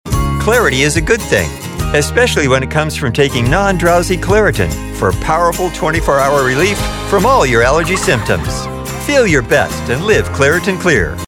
Claritin/ Friendly, Confident
Middle Aged